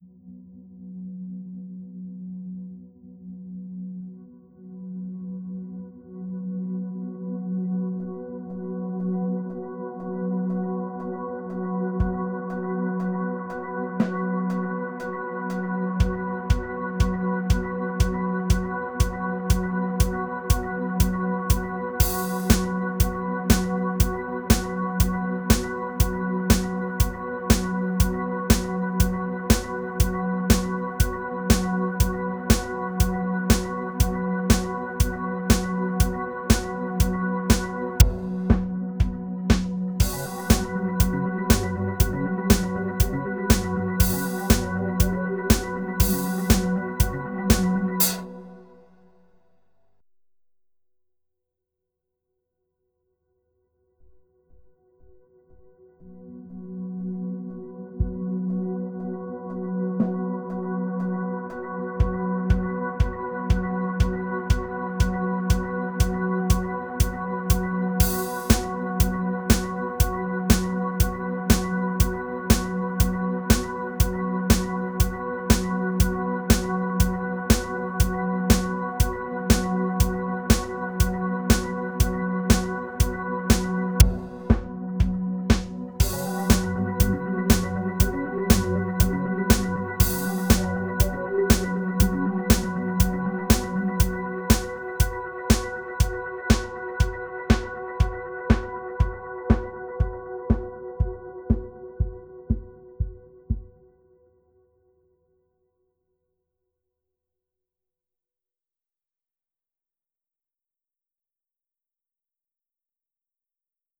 At a loss for ideas, I used the numbers of my birthday for intervals.